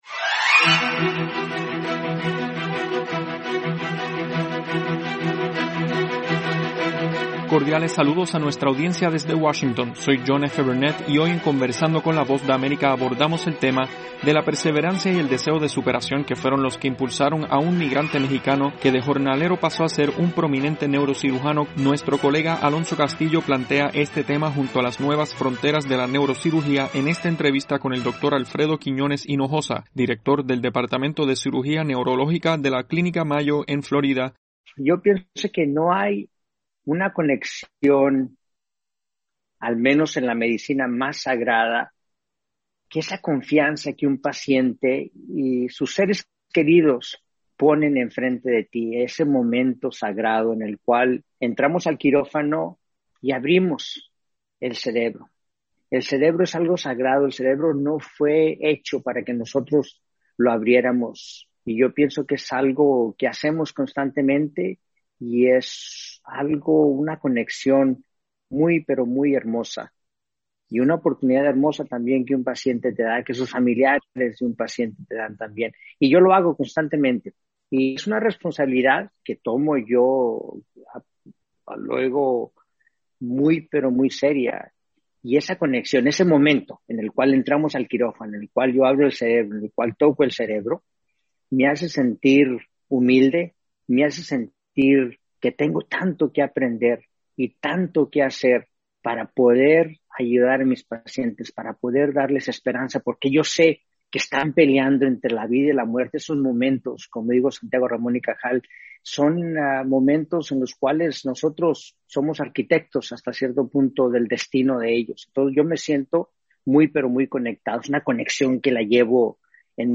Conversamos con el Dr. Aflredo Quiñones-Hinojosa, neurocirujano mexicano cuya carrera profesional es percibida como una de las más brillantes del mundo.